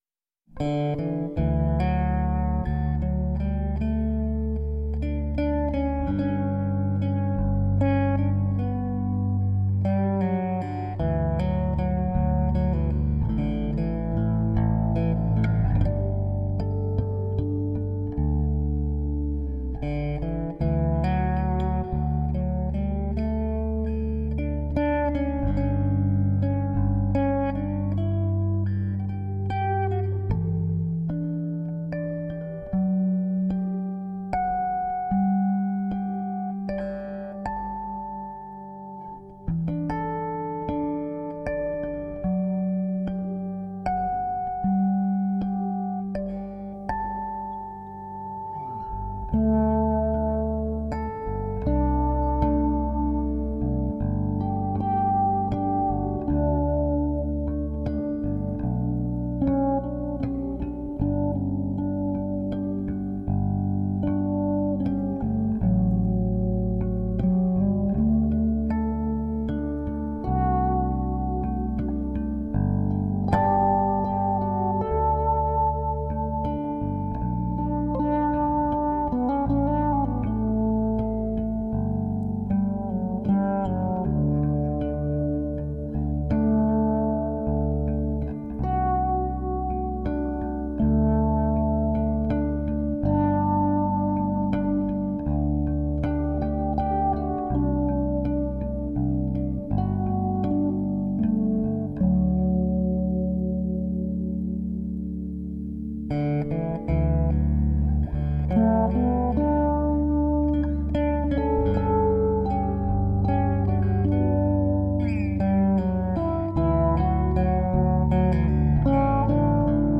Atmospheric jazz bass.
Tagged as: New Age, Ambient